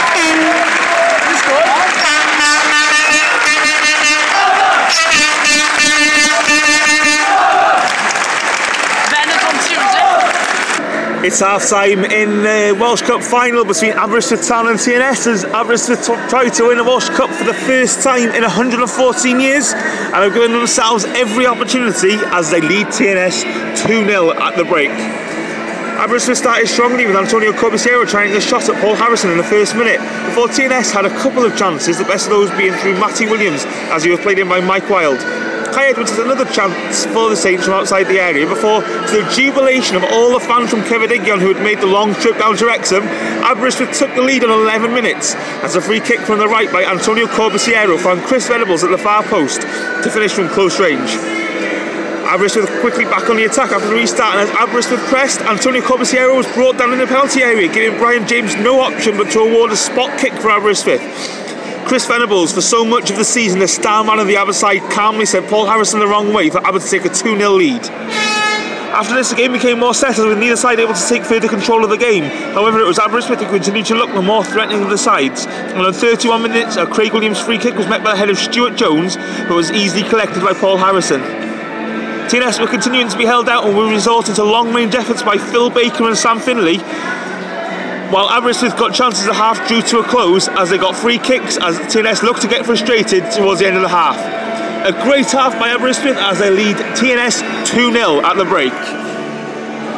half time report